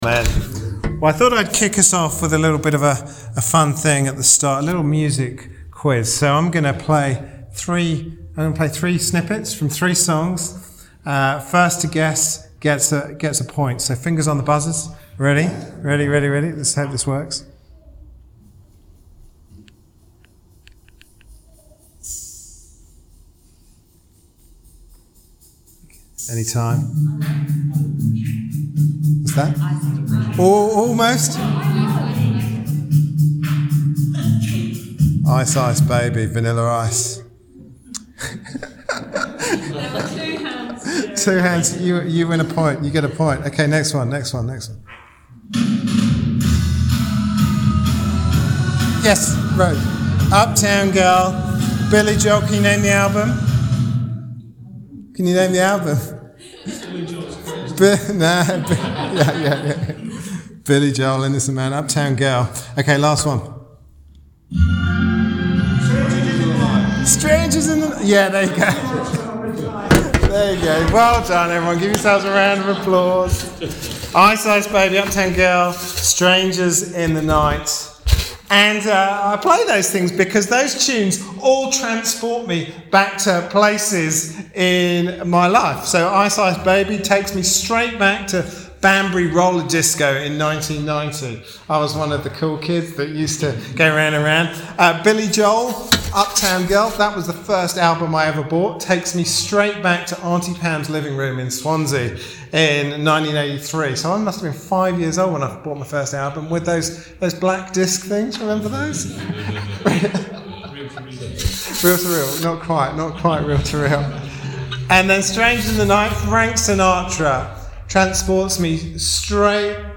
Passage: Exodus 15:1-21 Service Type: Sunday evening service Topics